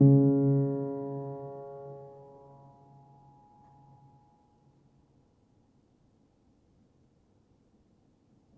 healing-soundscapes/Sound Banks/HSS_OP_Pack/Upright Piano/Player_dyn1_rr1_014.wav at main